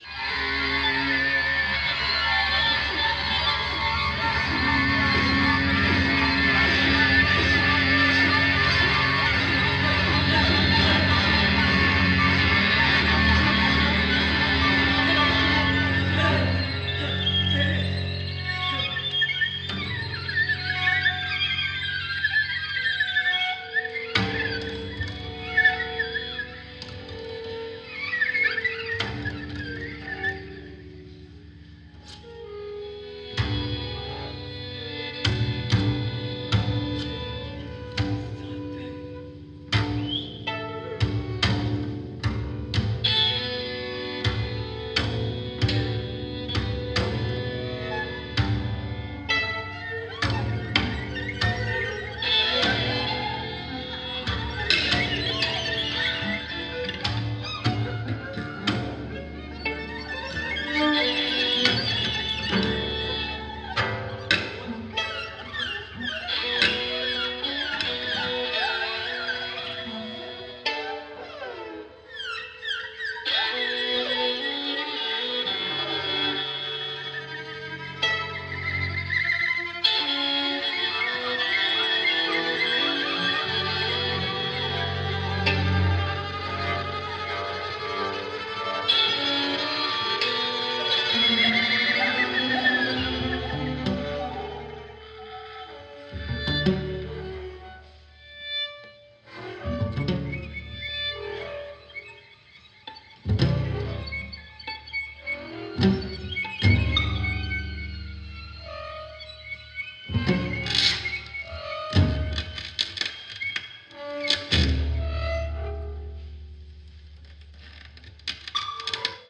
improvisation in large ensembles